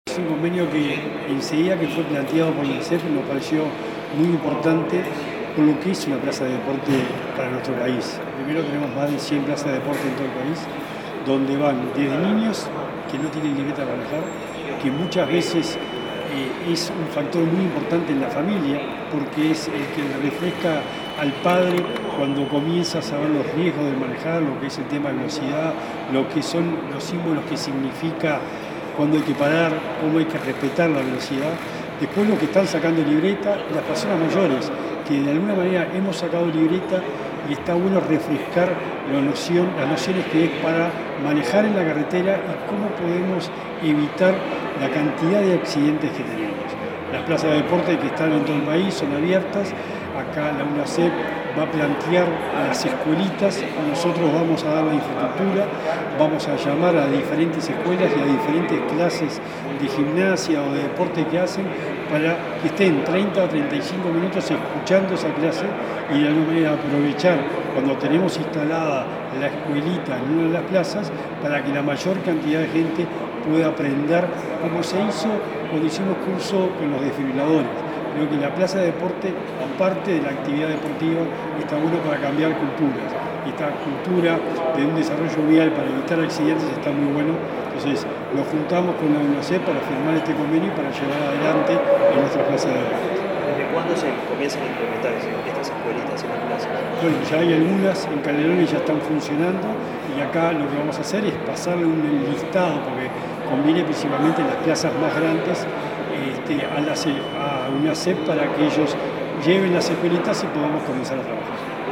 Declaraciones del secretario nacional del Deporte, Sebastián Bauzá
El secretario nacional del Deporte, Sebastián Bauzá, dialogó con Comunicación Presidencial en Torre Ejecutiva, luego de firmar un acuerdo con el